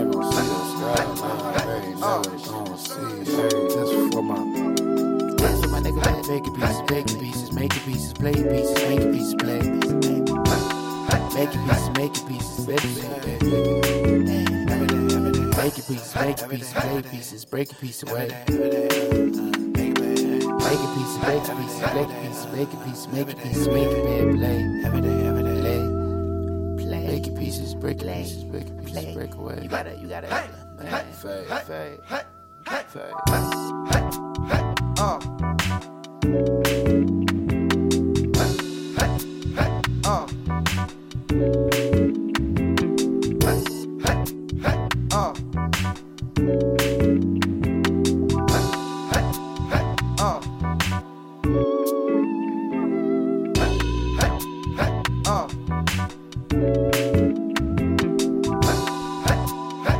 lush voicings